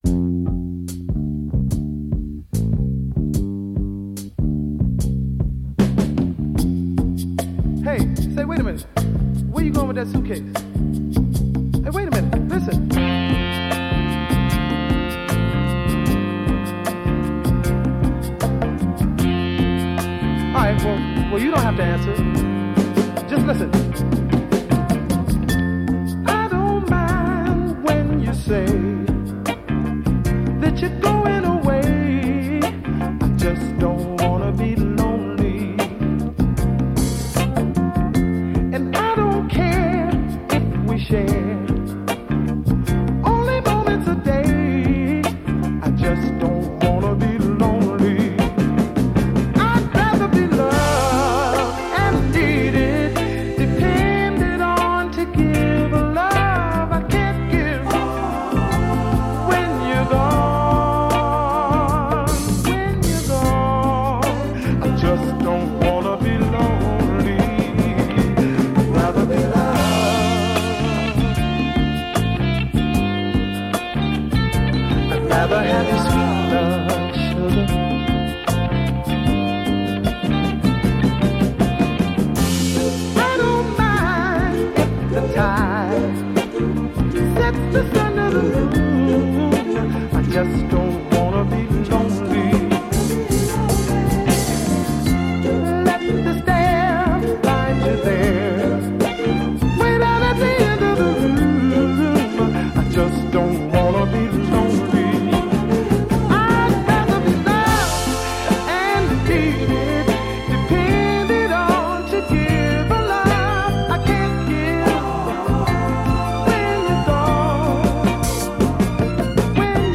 a few bars of spoken-word